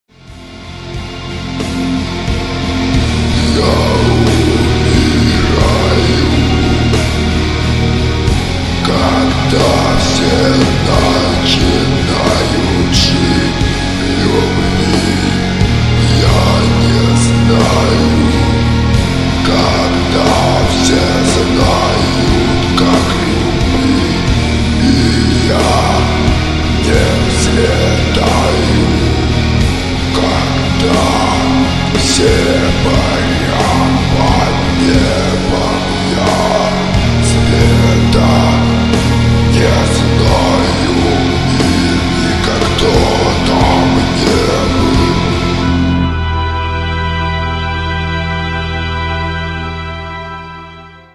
• Качество: 192, Stereo
Funeral Doom Metal